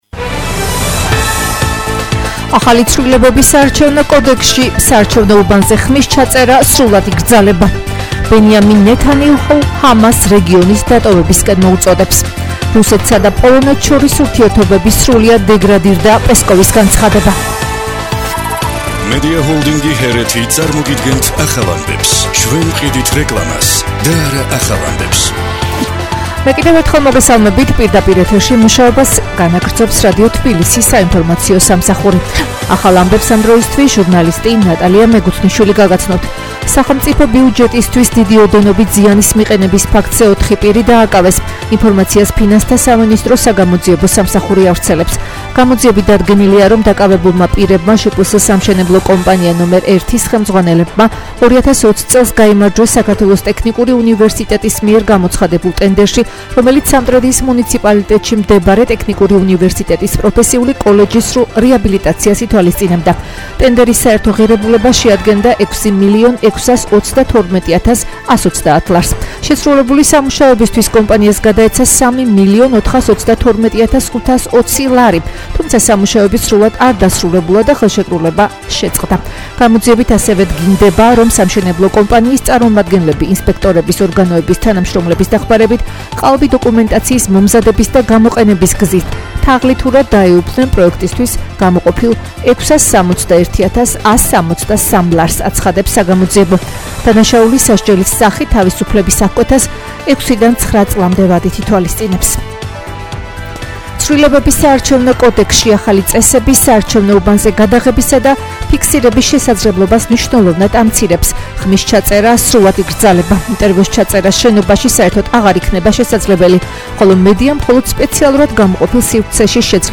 ახალი ამბები 15:00 საათზე